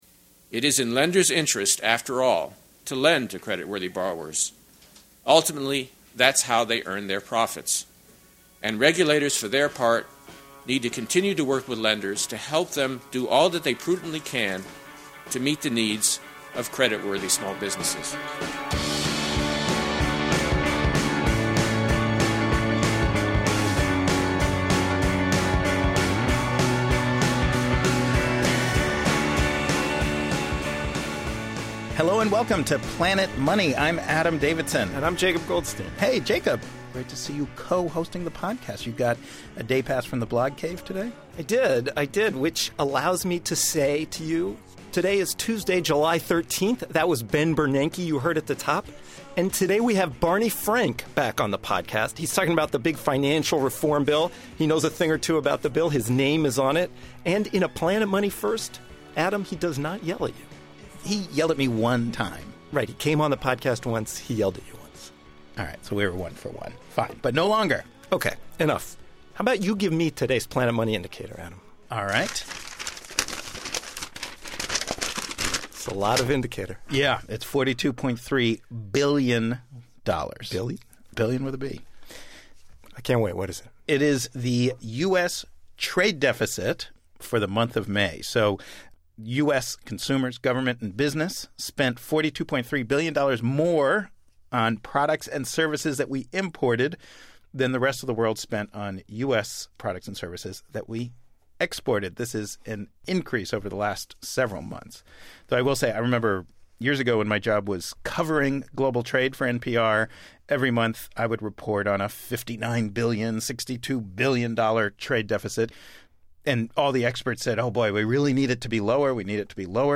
On today's podcast, we talk to Rep. Barney Frank, one of the guys whose name is on bill. He argues that Congress has to leave lots of issues to the regulators' discretion.